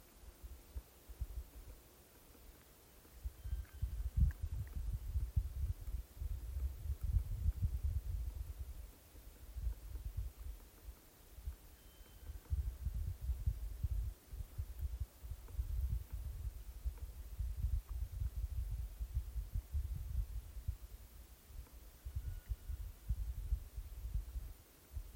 Pygmy Owl, Glaucidium passerinum
StatusVoice, calls heard